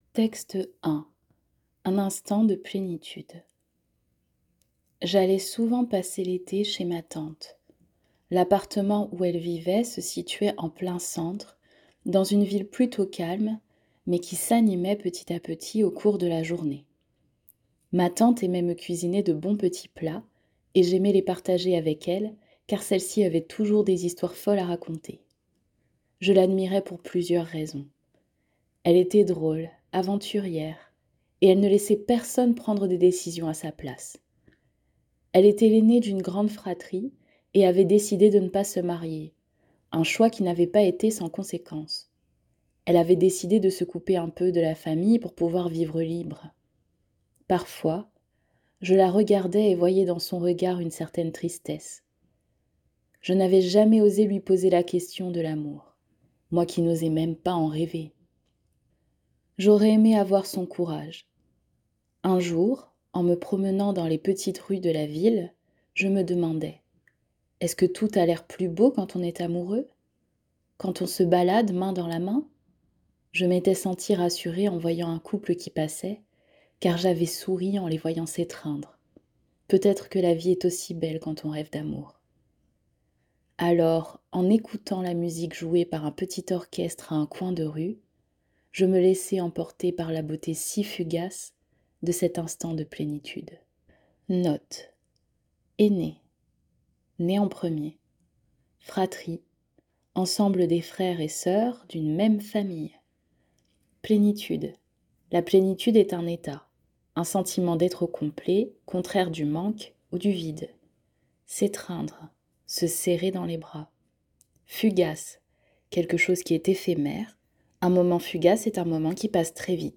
Pour télécharger votre extrait gratuit, cliquer sur le bouton téléchargement, le texte en pdf ainsi que sa lecture audio en MP3 sont disponibles.